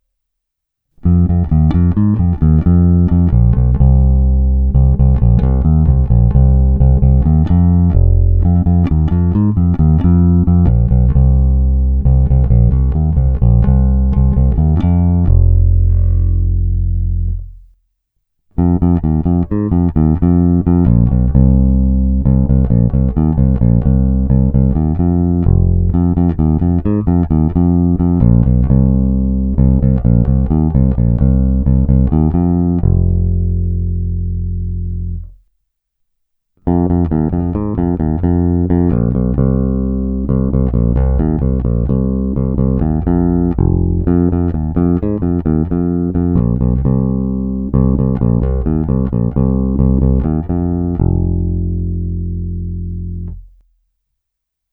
Zvuk je plný, pevný, bohatý středobasový základ doplňuje slušná porce kousavosti.
Není-li uvedeno jinak, následující nahrávky jsou provedeny rovnou do zvukové karty, jen normalizovány, jinak ponechány bez úprav. Tónová clona vždy plně otevřená.